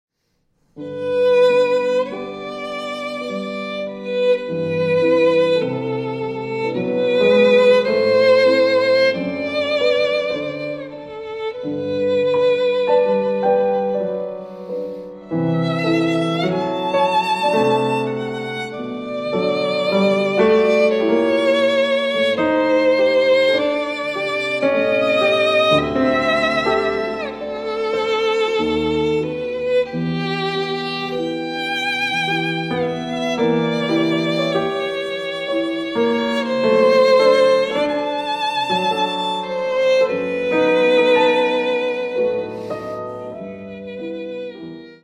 piano
Adagio – (6:28)